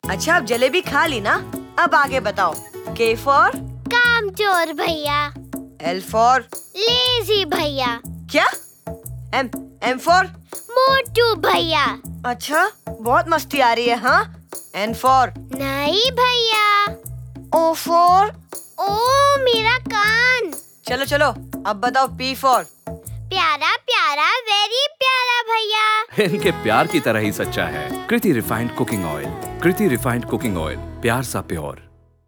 Kriti-Oil-K-M-N-O-P-radio.wav